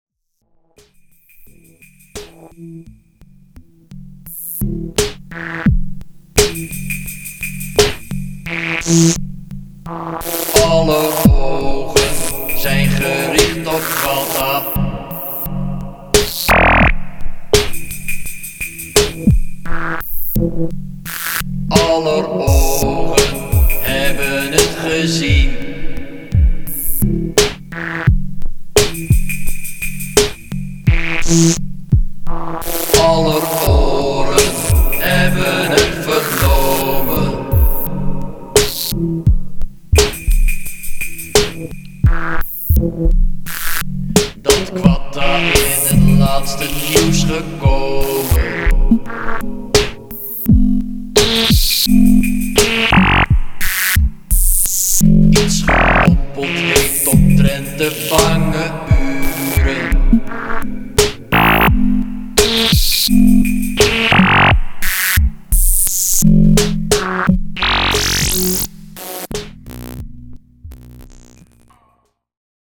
Blatantly non-MIDI and untidy and even untight in places